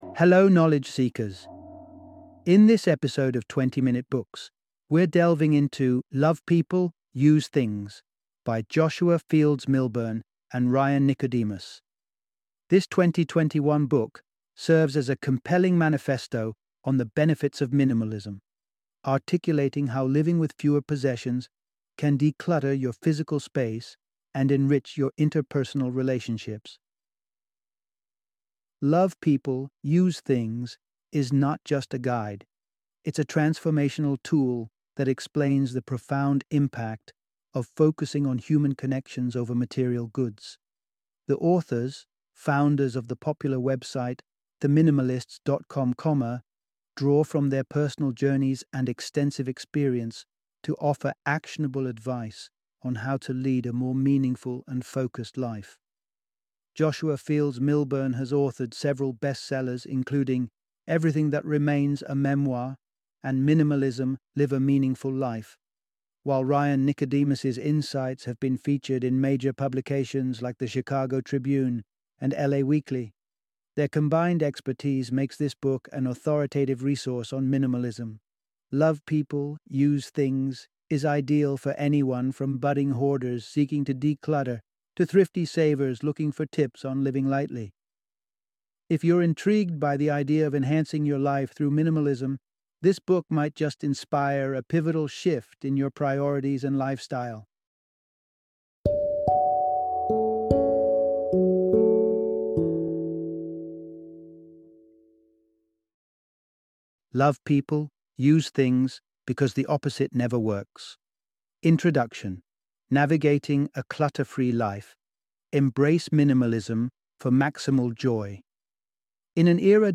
Love People, Use Things - Audiobook Summary